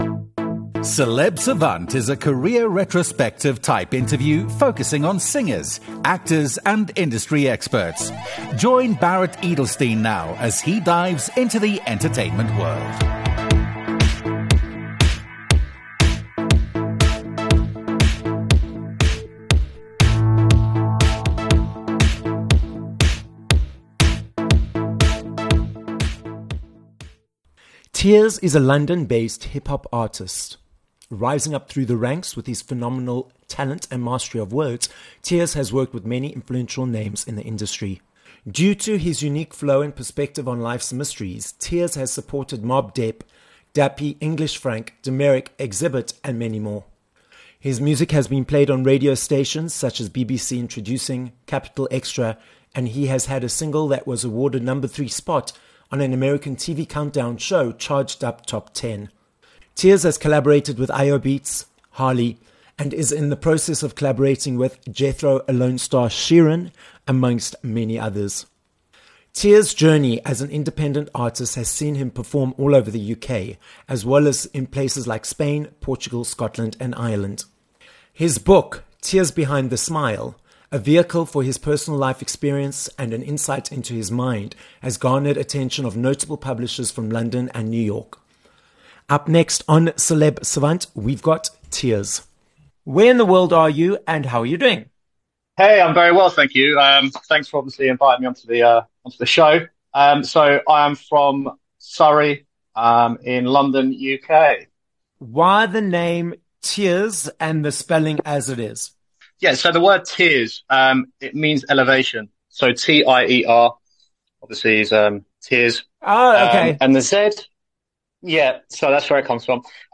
30 May Interview